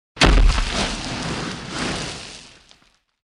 bodyfalldirt06.mp3